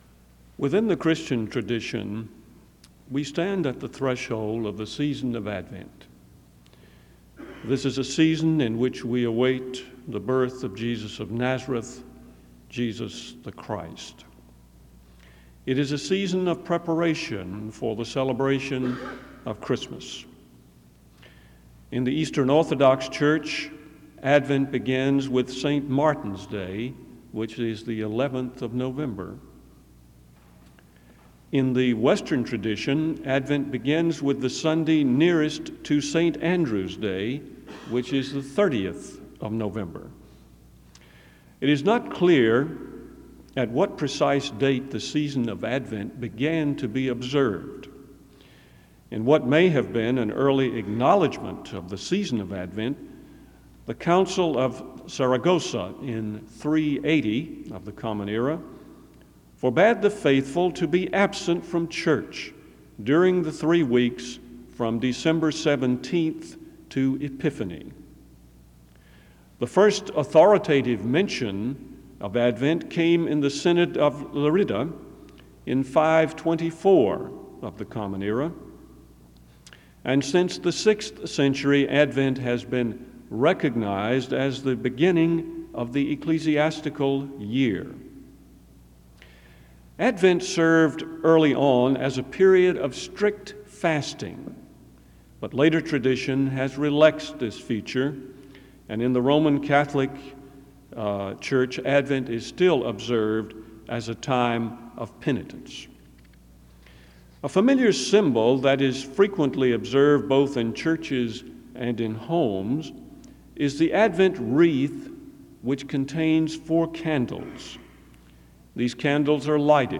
Página Principal SEBTS Chapel